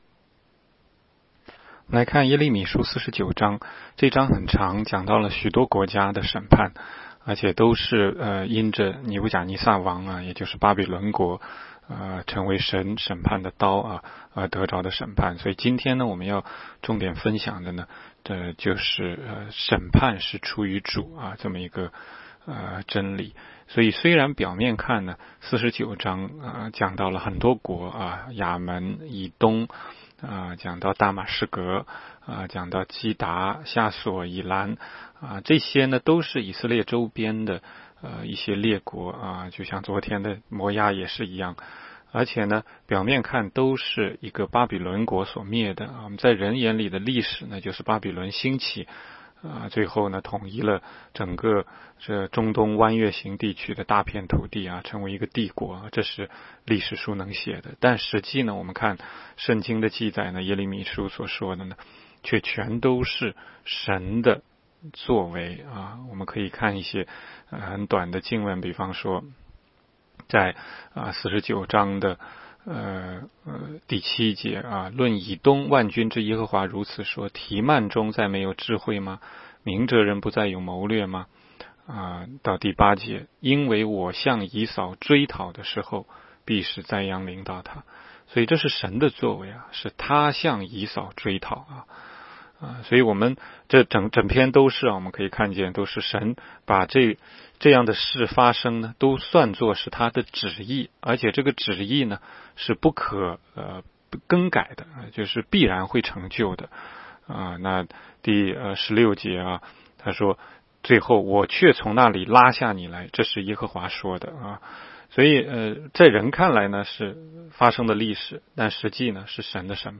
16街讲道录音 - 每日读经 -《耶利米书》49章